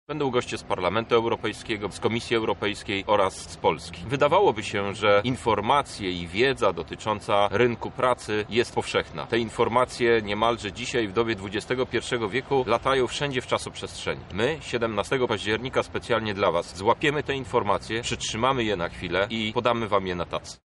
– mówi jeden z organizatorów, Poseł do Parlamentu Europejskiego Krzysztof Hetman.